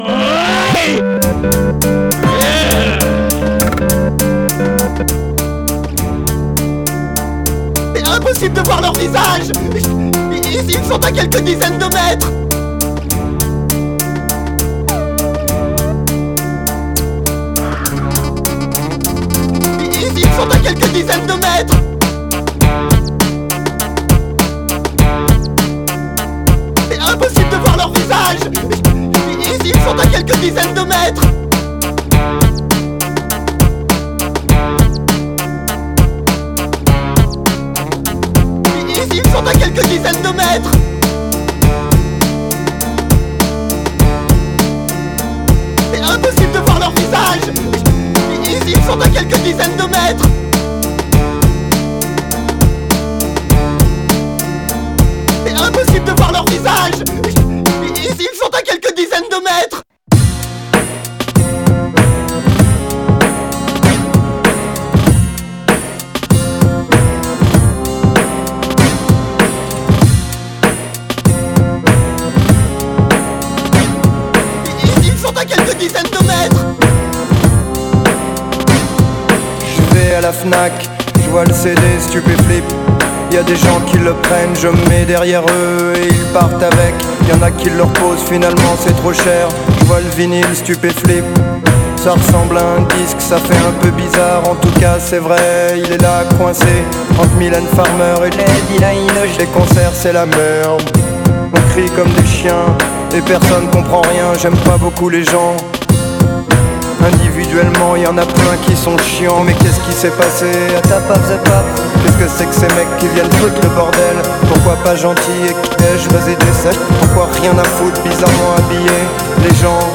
outro